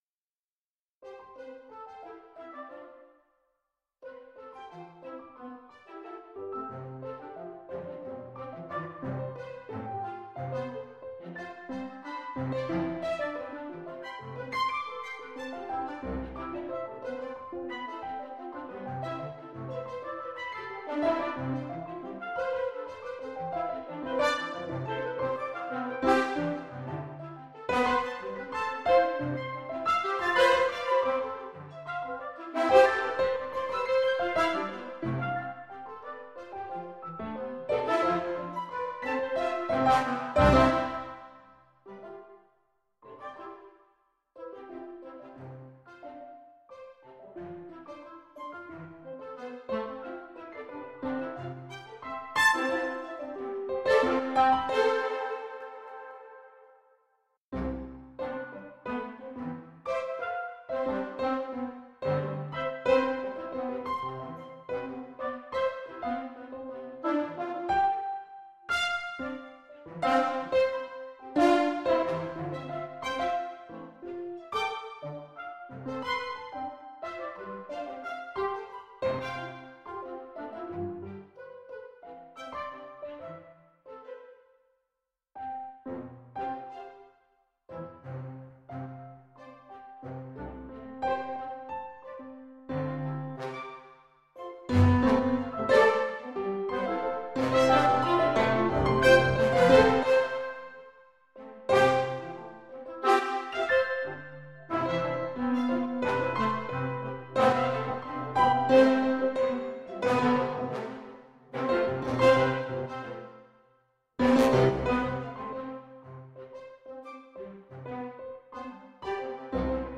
generative nonsense in C Major
Here, I’ve mapped some effects controls, as well as the velocity values of each note, to a motion sensor.
When I lunge at the screen, stabby accents occur.
Things sound more human, until they don’t.
randOrch2.mp3